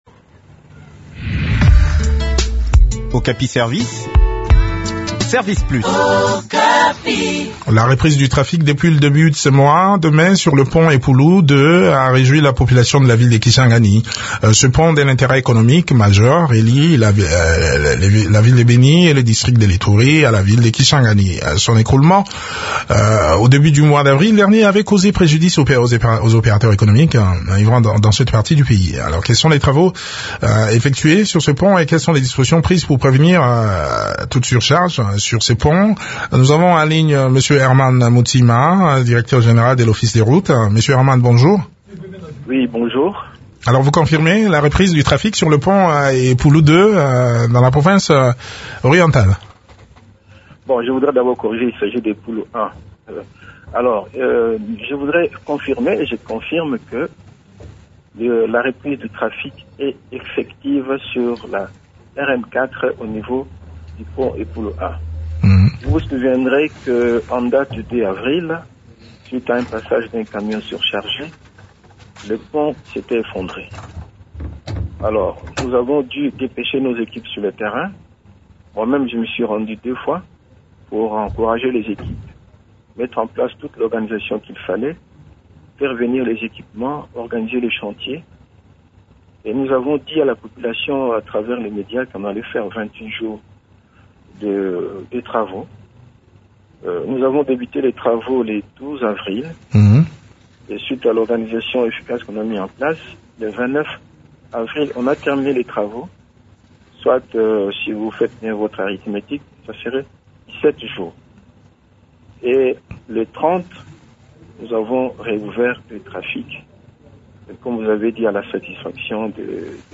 Le point de la situation sur terrain dans cet entretien